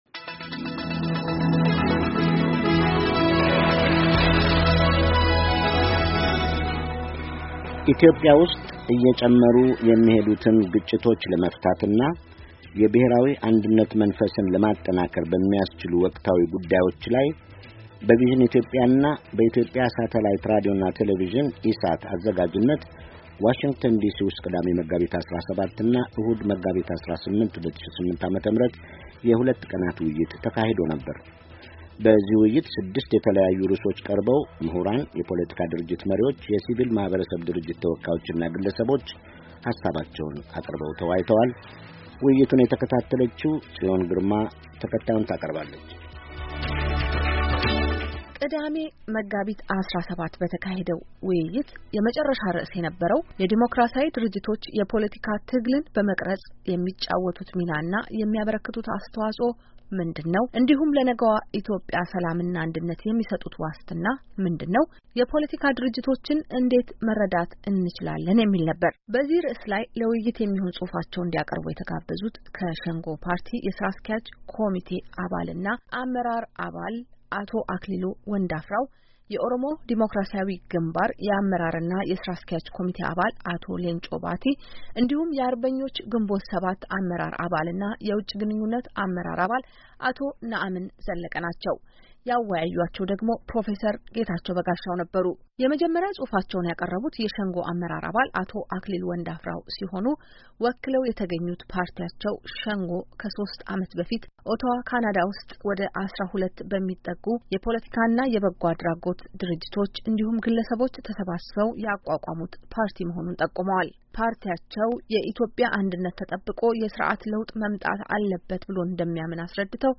የሦስት ፓርቲዎች ውይይት
በቪዠን ኢትዮጵያና በኢሳት መጋቢት 17 የተዘጋጀ የሦስት ፓርቲዎች ውይይት ኢትዮጵያ ውስጥ እየጨመሩ የሚሄዱትን ግጭቶች ለመፍታትና የብሔራዊ አንድነት መንፈስን ለማጠናከር በሚያስችሉ ወቅታዊ ጉዳዮች ላይ በቪዥን ኢትዮጵያ እና በኢትዮጵያ ሳተላይት ሬዲዮና ቴሌቭዥን (ኢሳት)አዘጋጅነት ዋሽንግተን ዲሲ ውስጥ ቅዳሜ መጋቢት 17 እና እሁድ መጋቢት 18 የሁለት ቀን ውይይት ተካሂዶ ነበር።